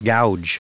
Prononciation du mot gouge en anglais (fichier audio)